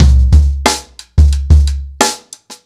• 120 Bpm Drum Loop Sample C Key.wav
Free breakbeat sample - kick tuned to the C note. Loudest frequency: 1031Hz
120-bpm-drum-loop-sample-c-key-yM3.wav